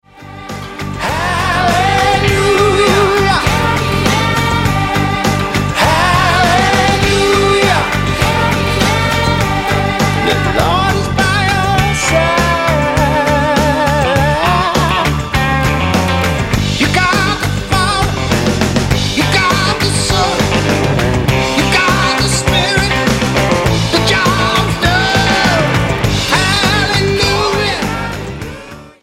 STYLE: Blues
satisfyingly raw collection of original gospel blues numbers